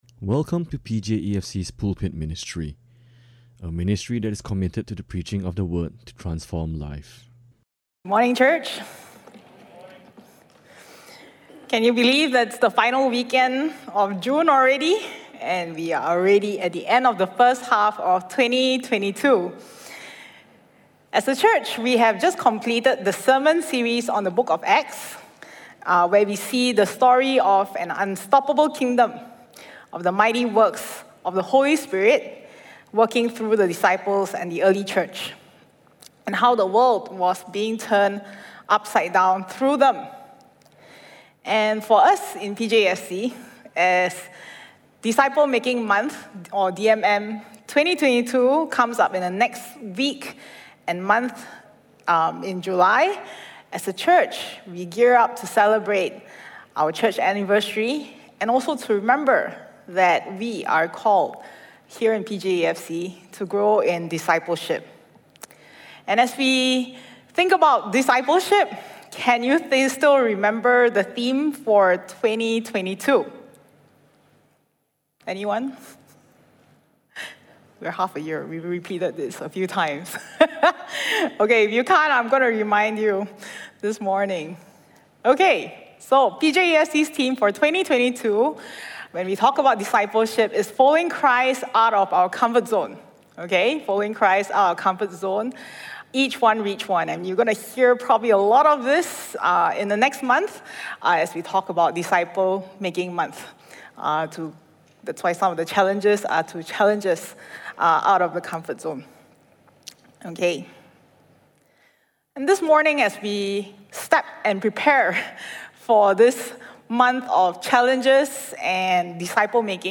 June 26, 2022 - A stand-alone sermon on discipleship.